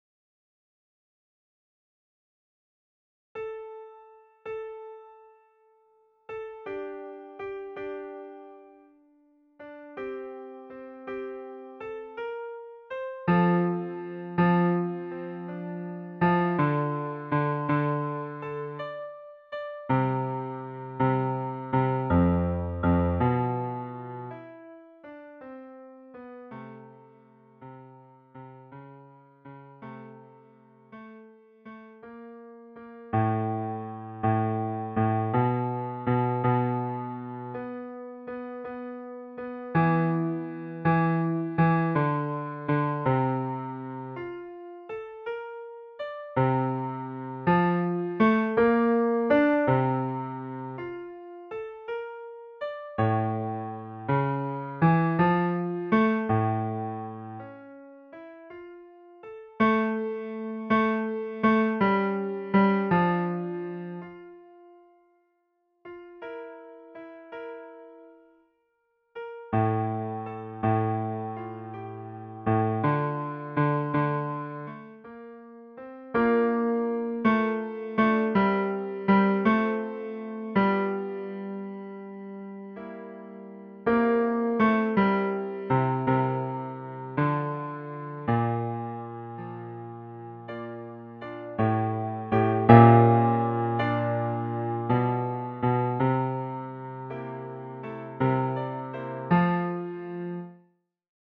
CHOEUR ET ECHO
Basses
le_vent_dans_la_foret_basses.mp3